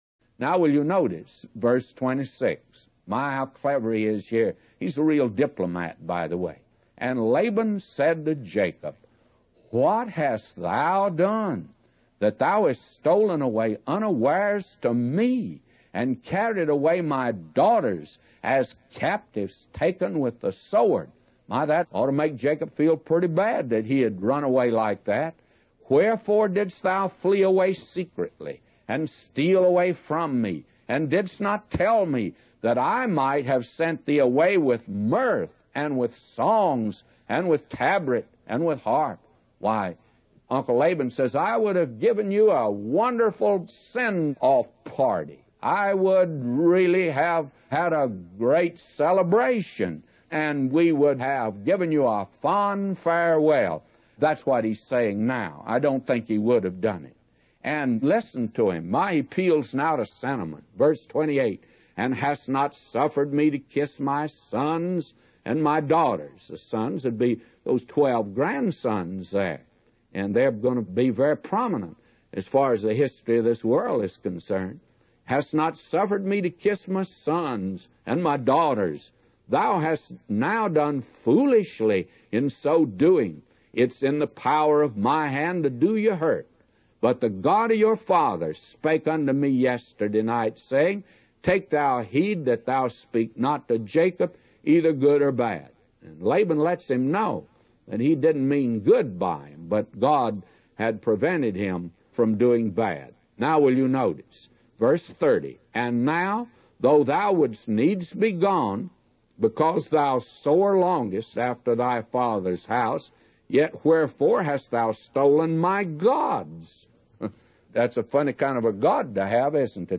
A Commentary By J Vernon MCgee For Genesis 31:26-999